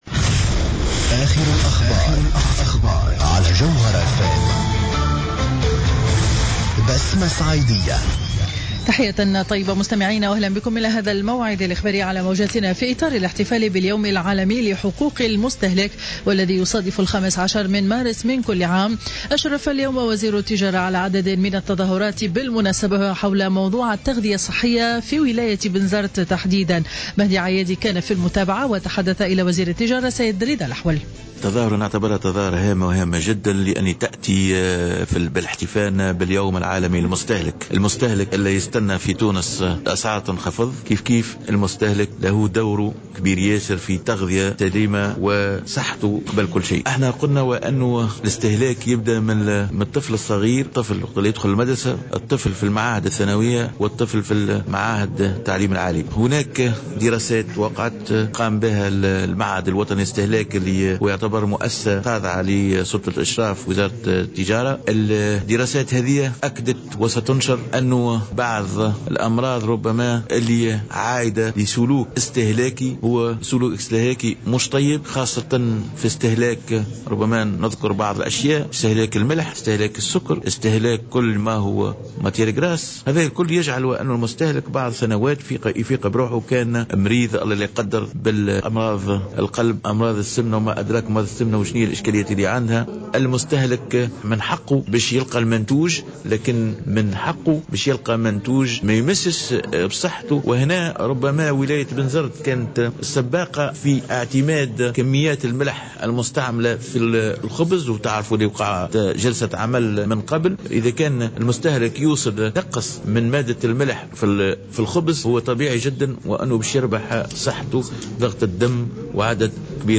نشرة أخبار منتصف النهار ليوم الأحد 15 مارس 2015